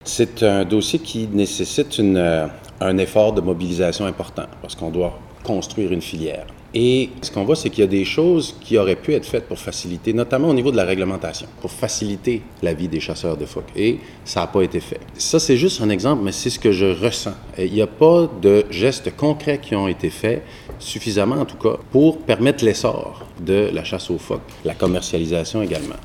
En entrevue avec CFIM